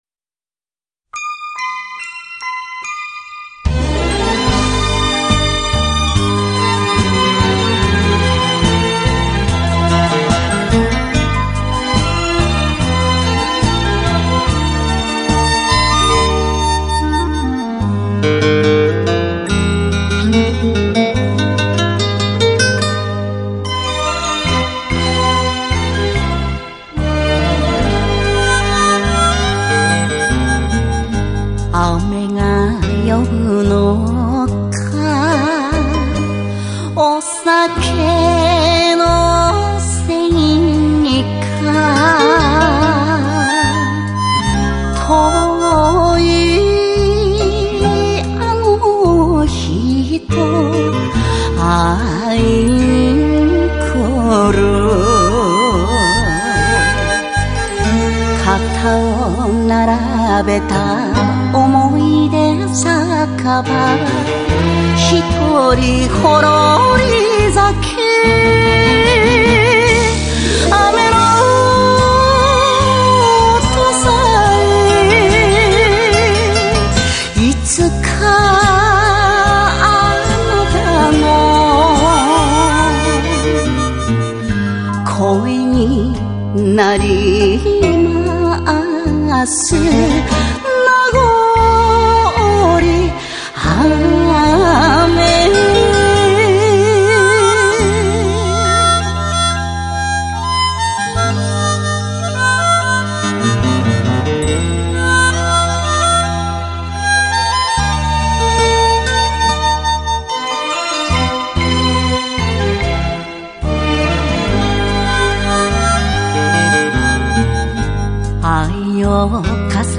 Жанр: enka